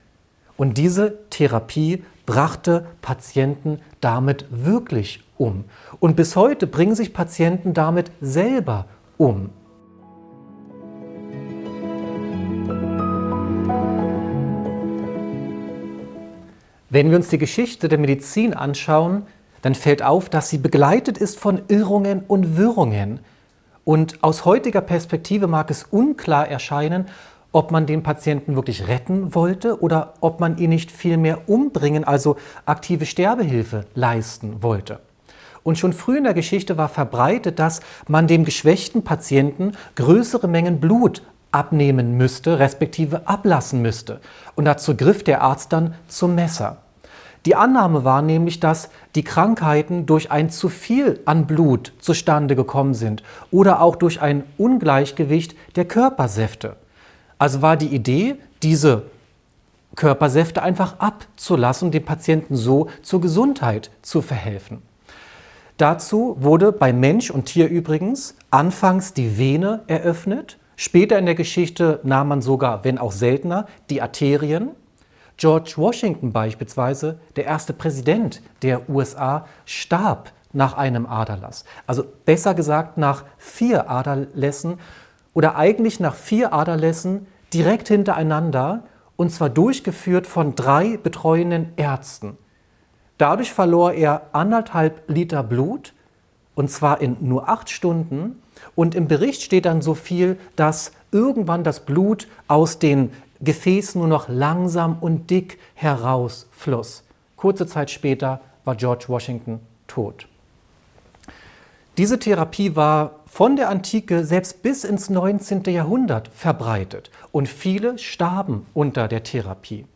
In diesem aufschlussreichen Vortrag wird die dunkle Seite der Medizingeschichte beleuchtet. Überraschende Therapien wie Aderlass und sogar Tabakrauch als Heilmittel zeigen, wie lange Patienten durch Fehlbehandlungen litten.